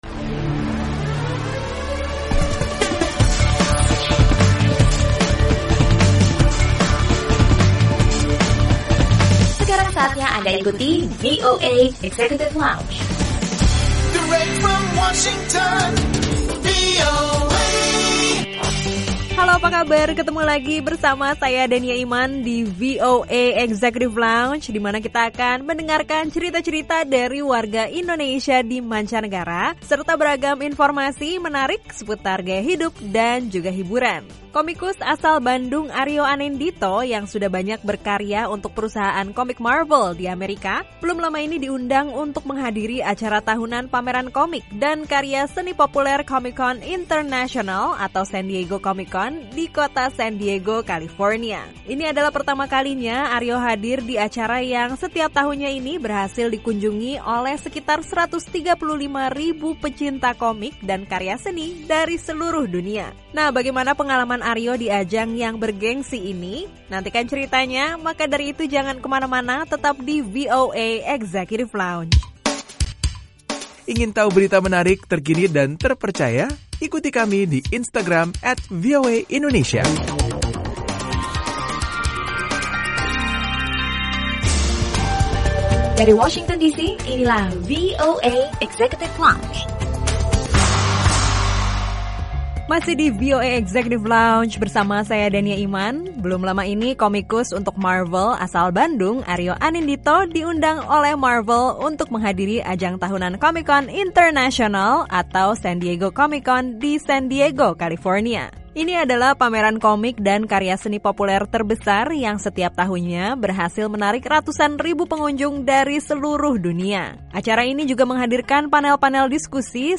Simak obrolan